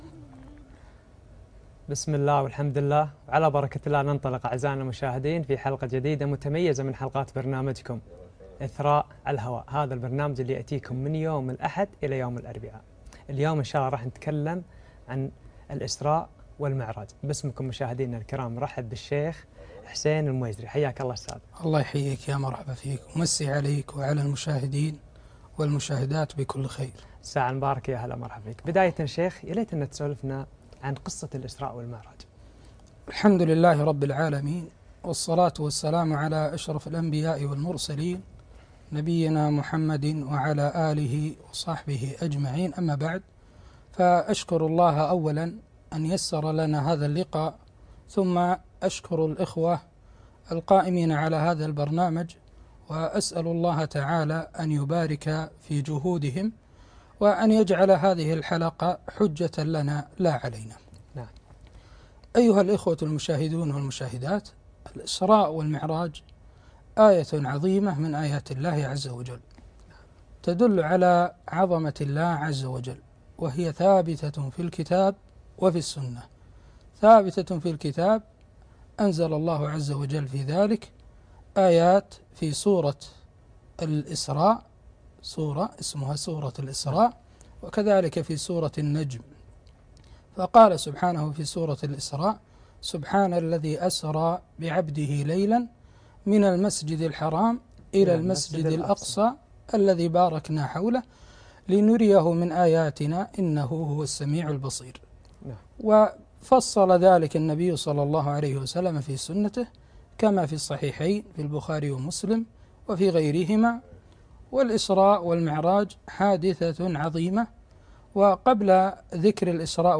الإسراء والمعراج - لقاء إذاعي